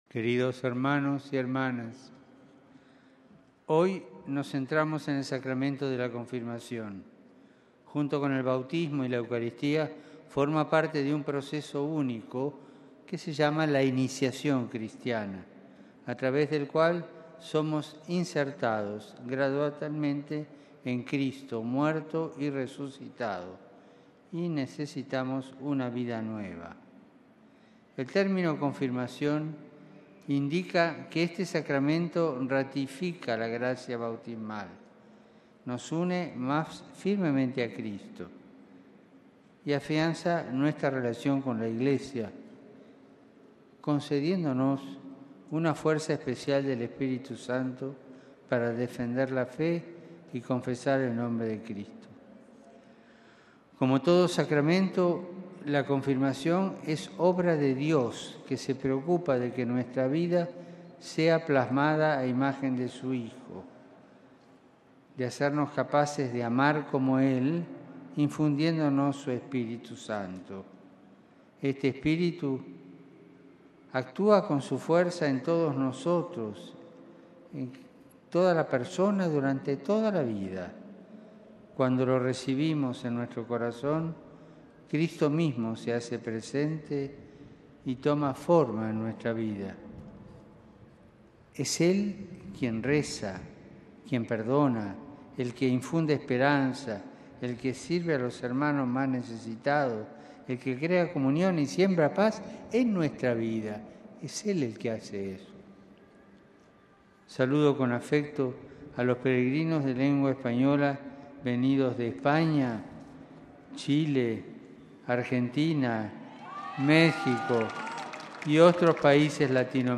(RV).- (Con audio y video) RealAudioMP3 La audiencia general de este miércoles estuvo dedicada al Sacramento de la Confirmación.
Palabras del Papa Francisco y saludo en nuestro idioma: